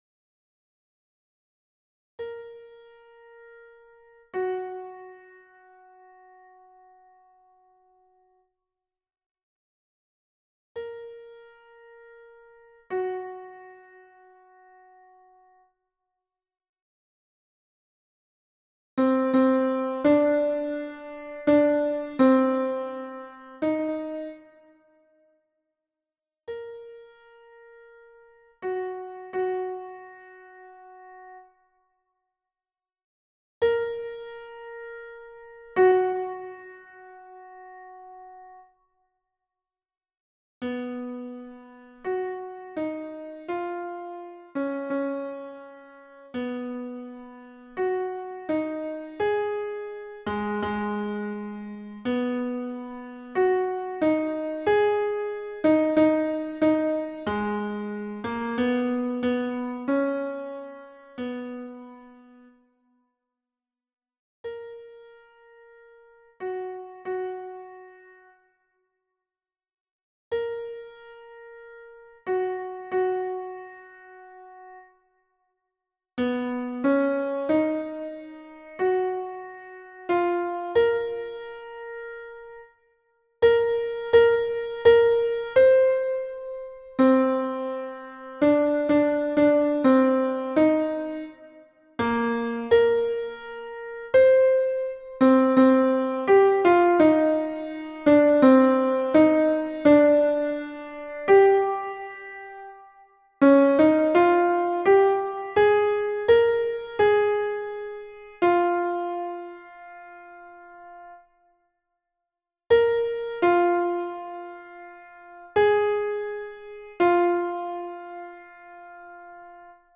MP3 version piano
Baryton